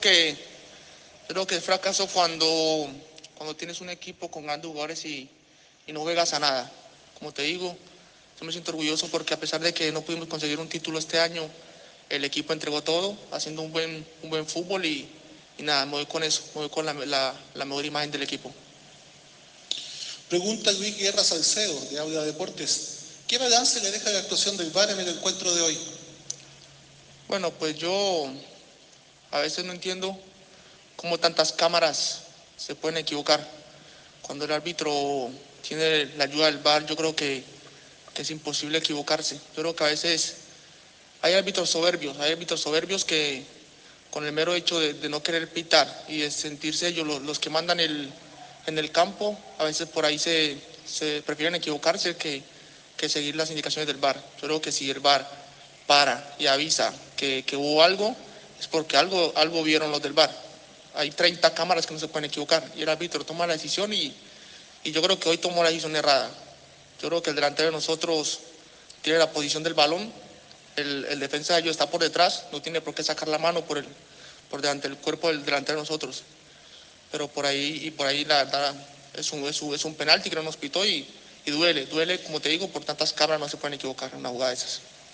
(Fredy Hinestroza, jugador del Junior)